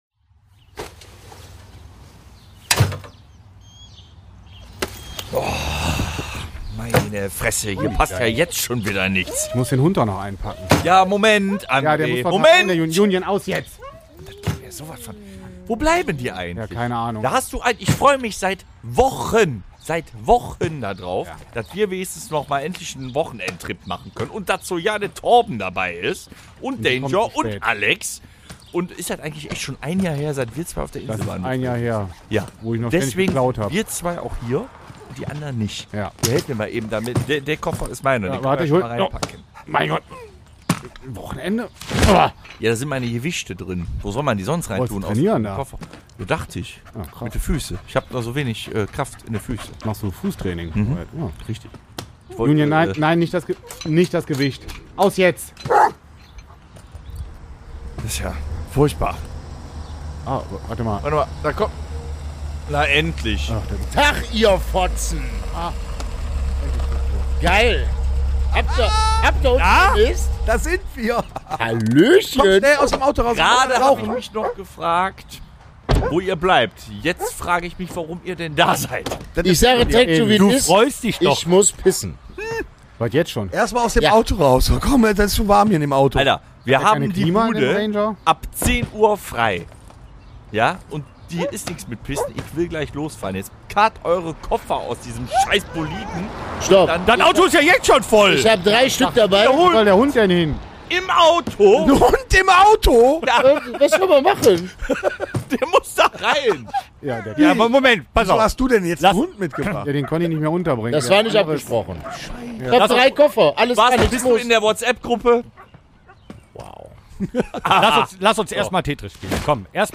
#250 | Halt mal eben mein Heineken (Hörspiel Special) ~ Rockhütte Podcast